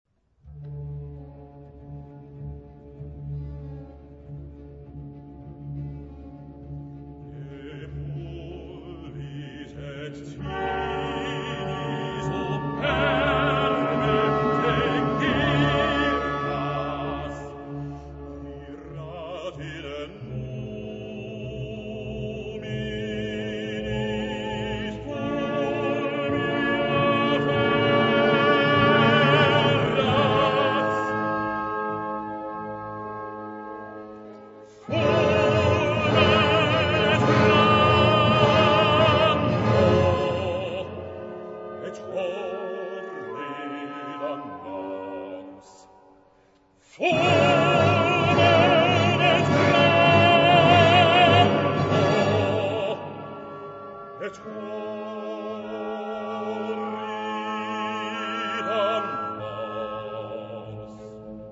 Genre-Style-Forme : Sacré ; Classique ; Hymne (sacré)
Caractère de la pièce : poétique
Type de choeur : SATB  (4 voix mixtes )
Solistes : Bass (1)  (1 soliste(s))
Instrumentation : Orchestre classique  (19 partie(s) instrumentale(s))
Instruments : Flûte (2) ; Hautbois (2) ; Basson (2) ; Cor (2) ; Clarine (2) ; Timbale (2) ; Trombone (3) ; Violon (2) ; Alto (div) ; Violoncelle et Contrebasse ; Orgue (1)
Tonalité : ré mineur